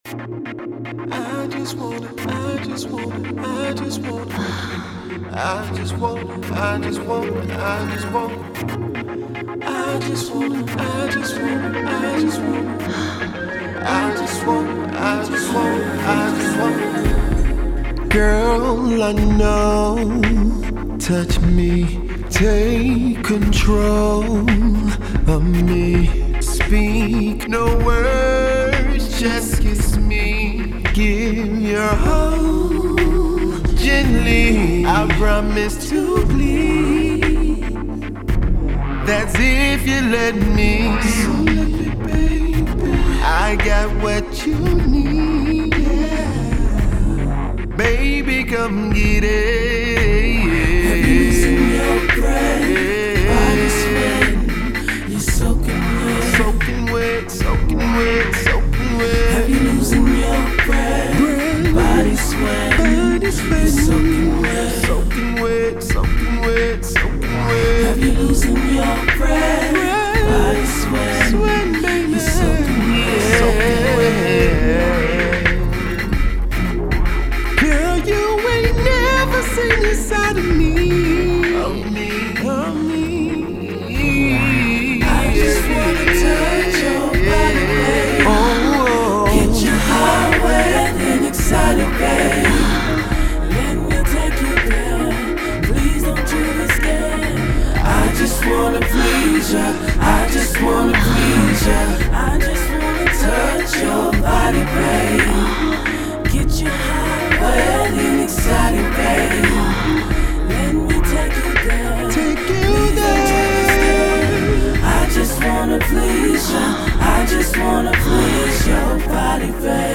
silky-smooth and soulful vocals
R&B